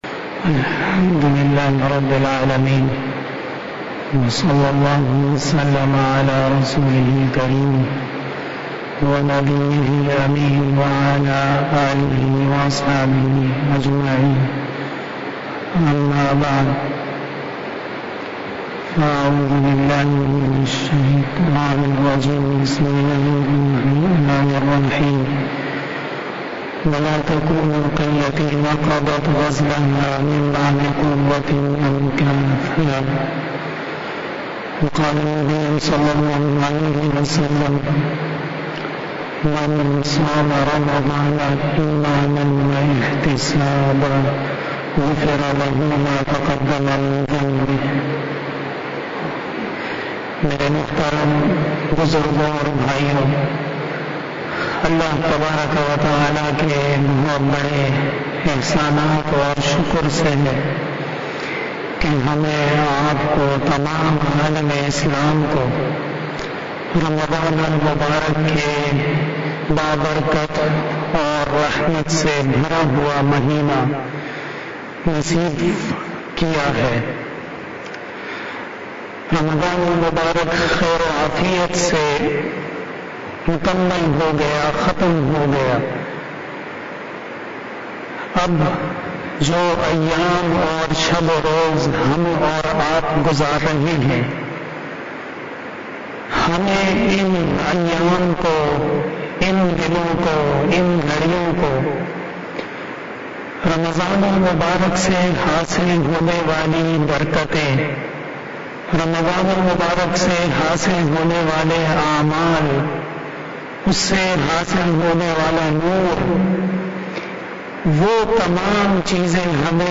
بیان شب جمعۃ المبارک ٠٦ شوال ۱۴۴۱ھ بمطابق ٢٨ مئی ۲۰۲۰ء